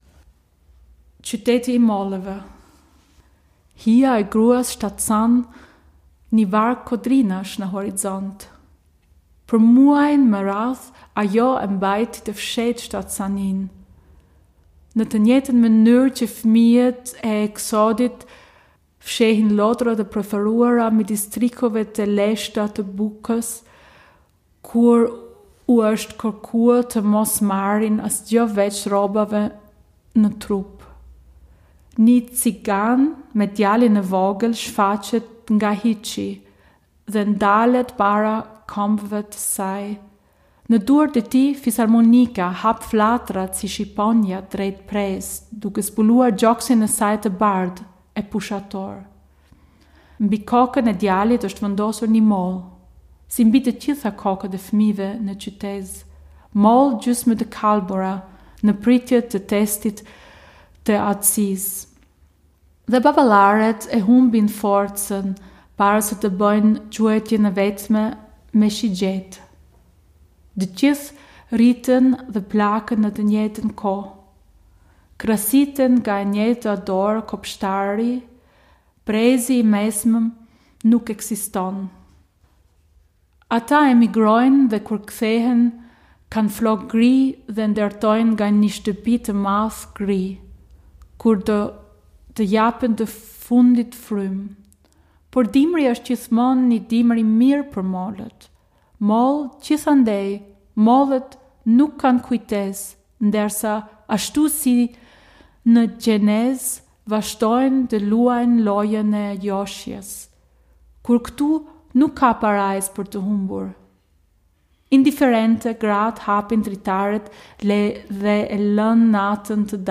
Gedicht-Die-Stadt-der-Apfel.mp3